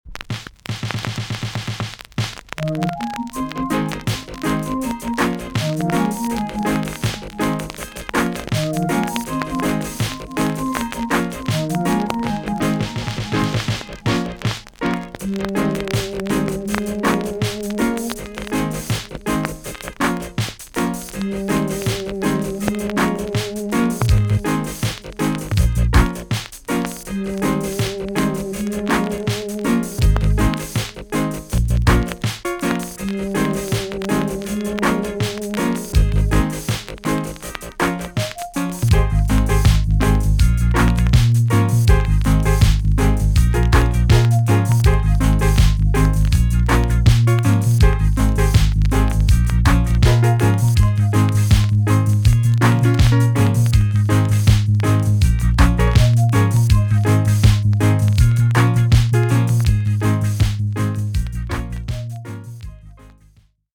TOP >80'S 90'S DANCEHALL
B.SIDE Version
VG+~VG ok 少し軽いチリノイズが入ります。